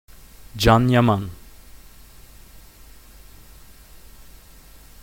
Se ti stai chiedendo come si pronuncia correttamente Can Yaman, sappi che trattandosi di un nome turco la “C” ha il suono della nostra “G” di gelato, pertanto il nome va letto come “Gian” e il cognome “Iaman”: Gian Iaman.
Audio pronuncia corretta Can Yaman
Come avrai sentito, il nome Can non va letto con la “C” di cane, cosa che sarebbe corretta qualora il nome fosse Kaan.
pronuncia-corretta-can-yaman.mp3